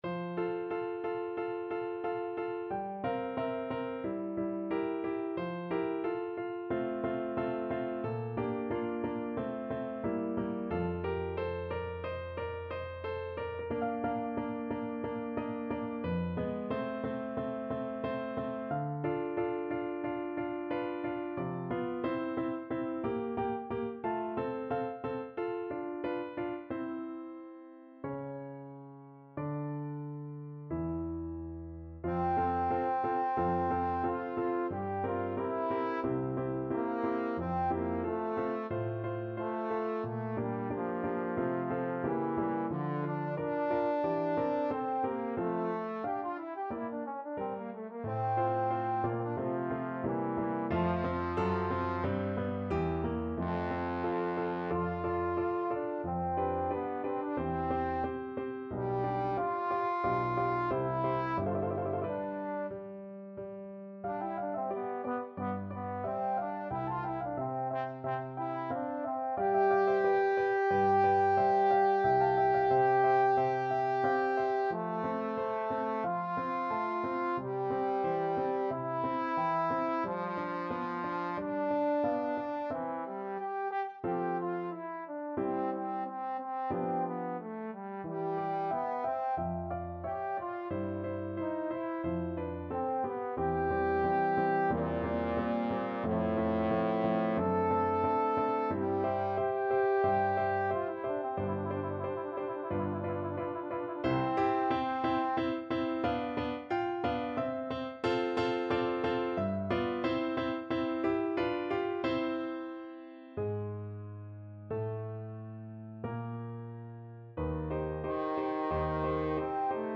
Classical Mozart, Wolfgang Amadeus Bassoon Concerto K.191, 2nd Movement, Andante ma Adagio Trombone version
Trombone
F major (Sounding Pitch) (View more F major Music for Trombone )
F3-A5
=45 Andante ma Adagio
2/2 (View more 2/2 Music)
Classical (View more Classical Trombone Music)